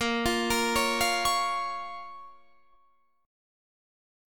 Listen to Bbm strummed